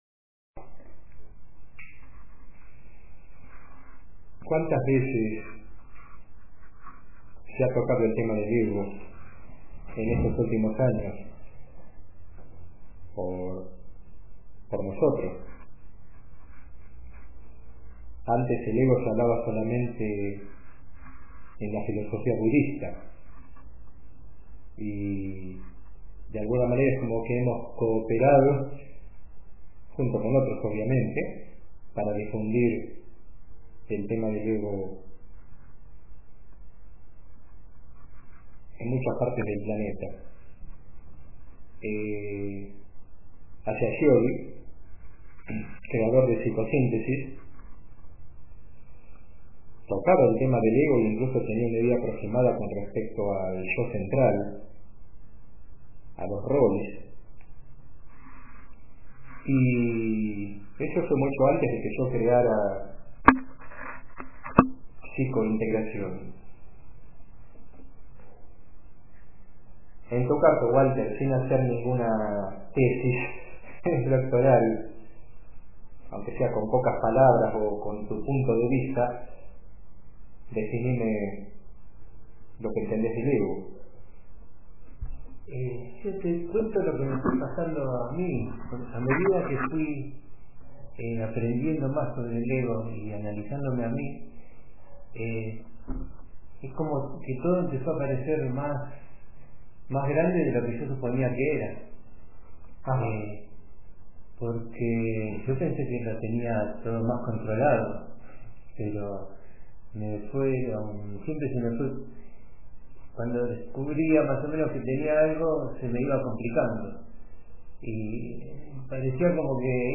Sesión en MP3